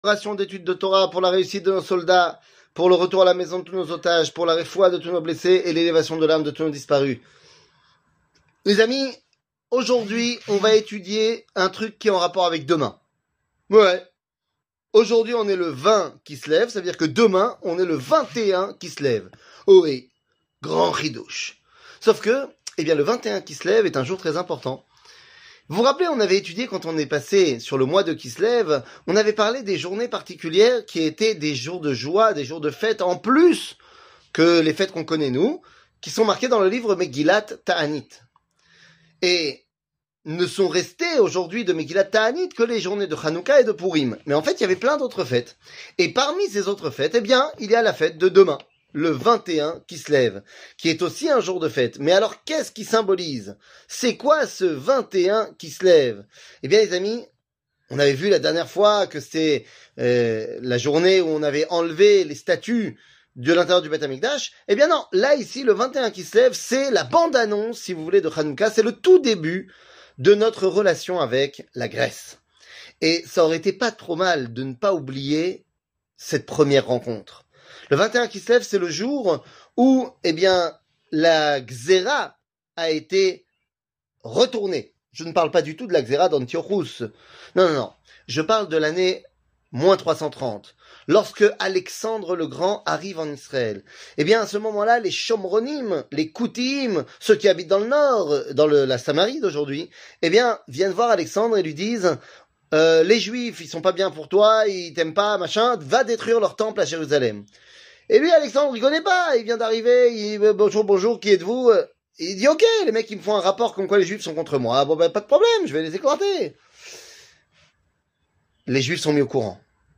Le 21 Kislev , La bande annonce de Hanouka 00:05:33 Le 21 Kislev , La bande annonce de Hanouka שיעור מ 03 דצמבר 2023 05MIN הורדה בקובץ אודיו MP3 (5.07 Mo) הורדה בקובץ וידאו MP4 (8.21 Mo) TAGS : שיעורים קצרים